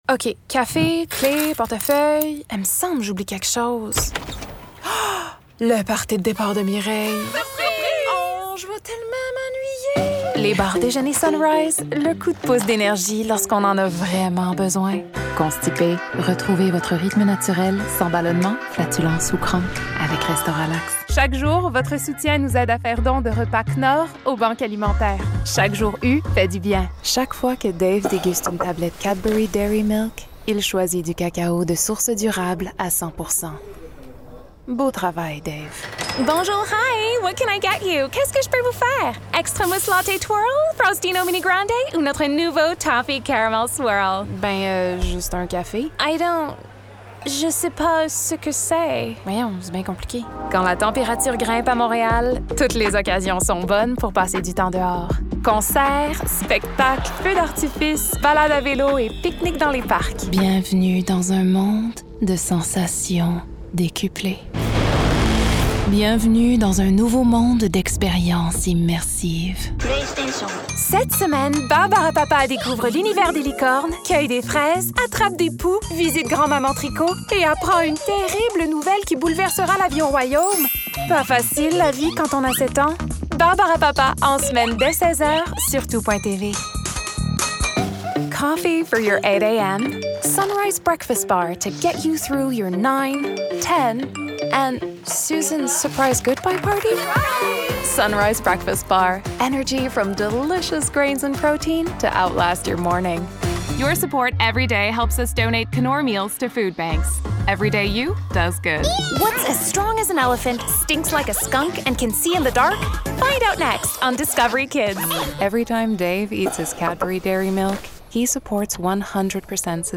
Showreel